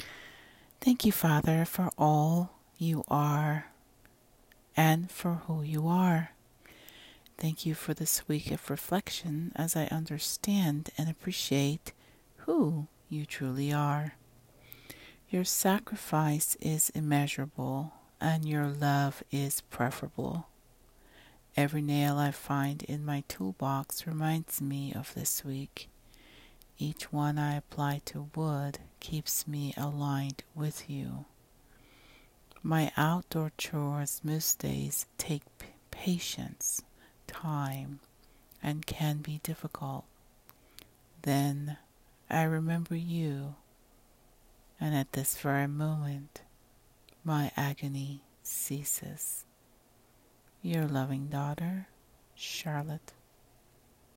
My prayer:
Spoken words: